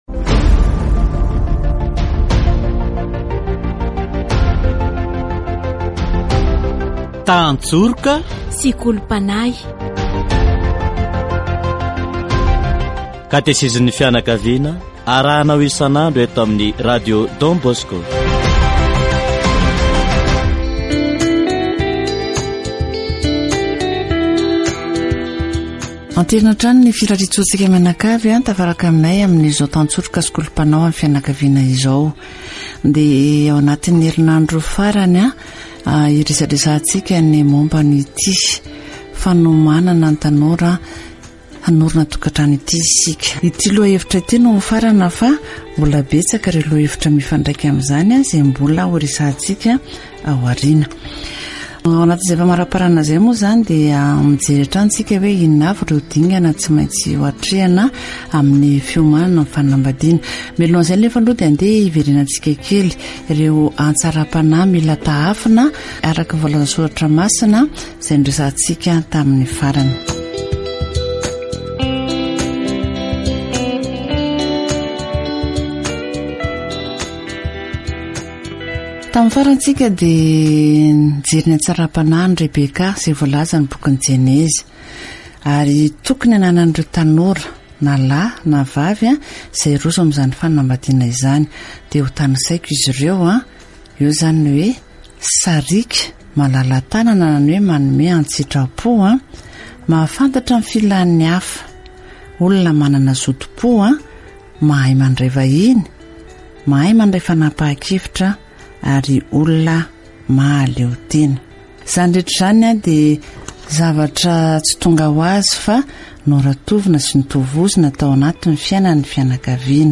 Rehefa voalamina ny safidin'ny vady dia manomboka ny fotoanan'ny fifamofoana ; fotoana mahafinaritra sy mampientam-po. Ara-tsoratra Masina dia misy fototra ny fiarahana : manamafy izany ilay ao amin'ny lalàn'i Mosesy hoe ny fanambadiana dia miorina mafy, ary misy fepetra mazava momba izany. Katesizy momba ny tanora miomana hiditra tokan-trano